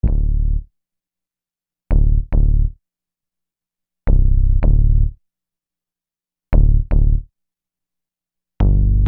Bass 02.wav